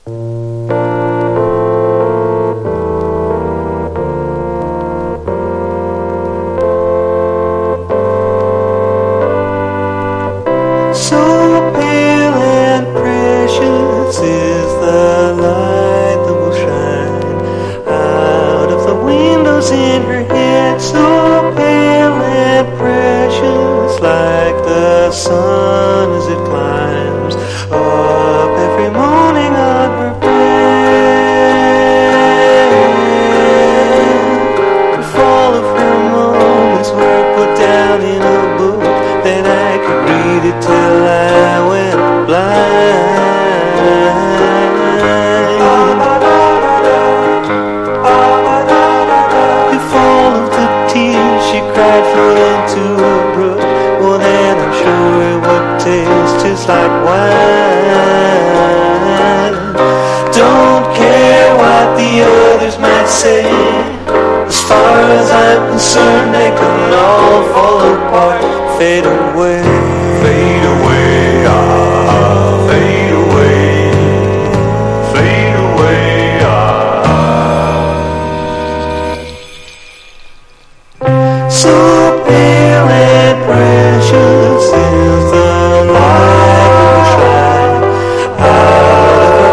60'Sサイケ趣味全開の名盤！